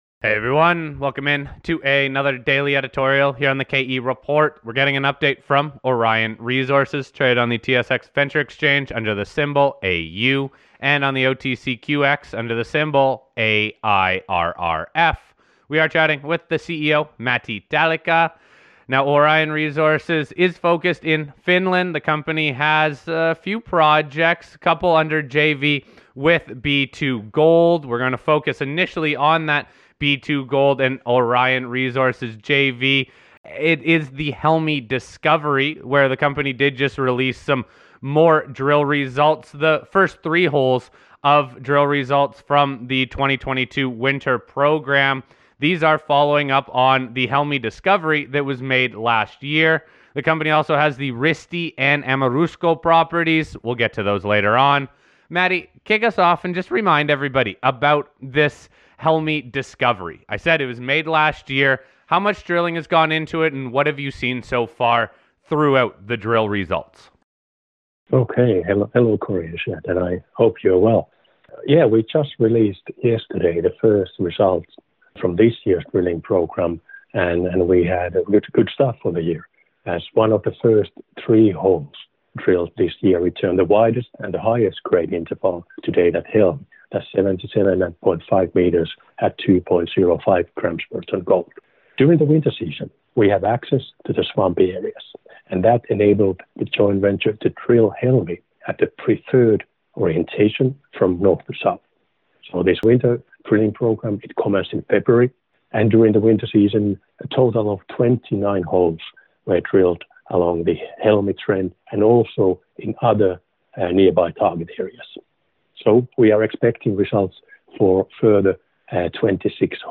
The conversation then transitioned over to recapping prior exploration work and the strategy moving forward for exploring the Risti regional targets and a continued focus on the Aamurusko Property. The Company outlined multiple broad zones of gold mineralization at the NW and Gap Zones based on previous soil in till anomalies and geophysical survey targets.